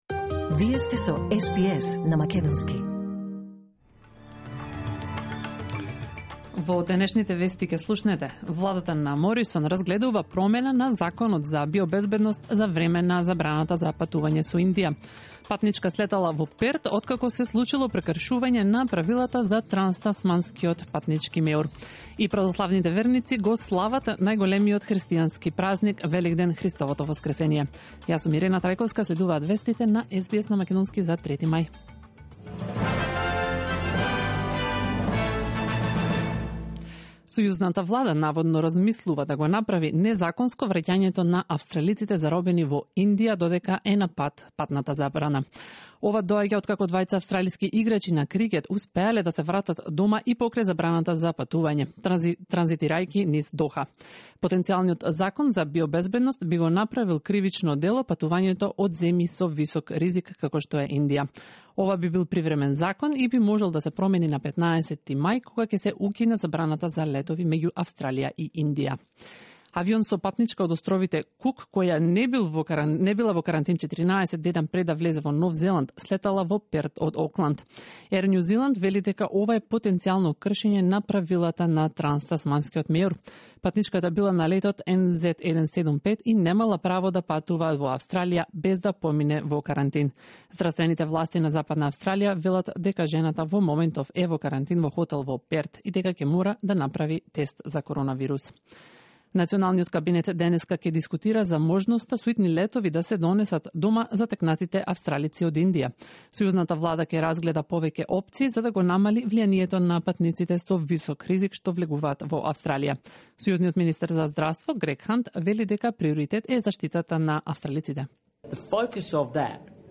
SBS News in Macedonian 3 May 2021